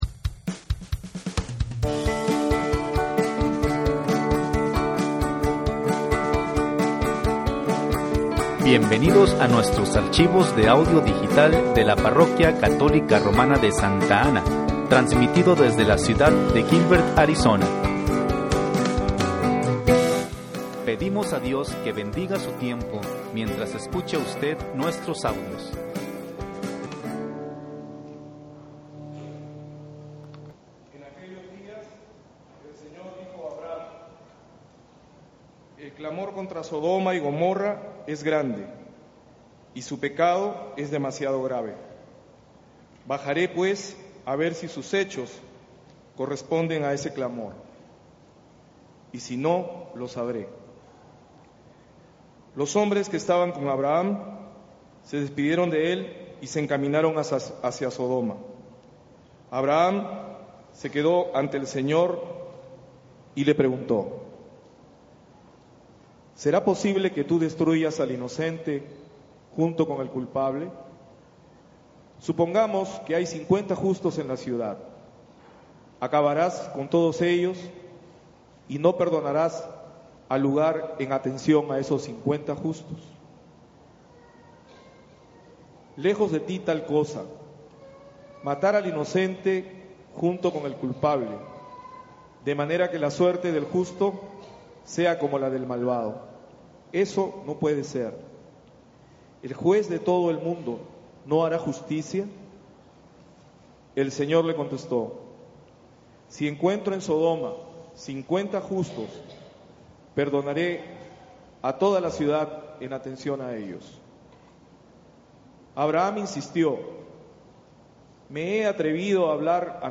XVII Domingo Tiempo Ordinario (Lecturas)